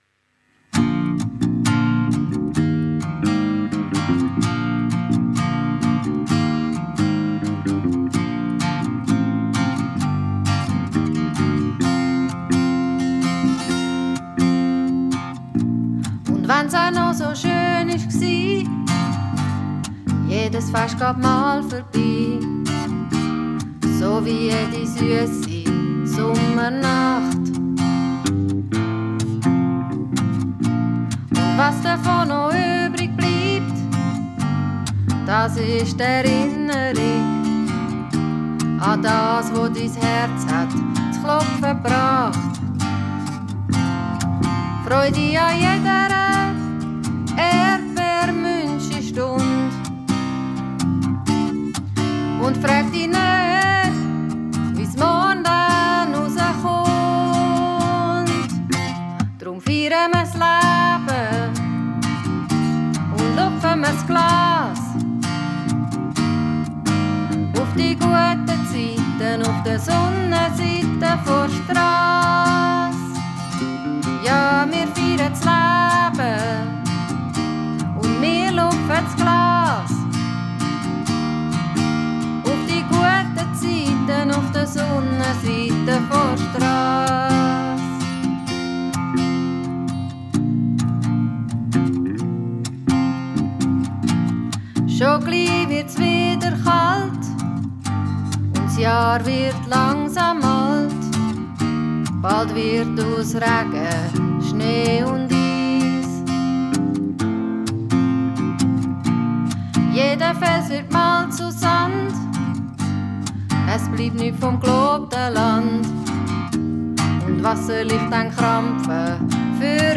Pop, Soul, Blues, Folk und Jazz-Standards